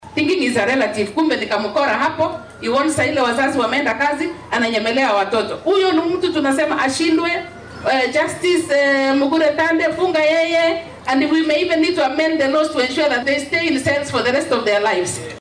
Baaqan ayay jeedisay xilli ay ka qayb gashay munaasabad lagu daahfurayey maxkamadda carruurta ee Kilifi.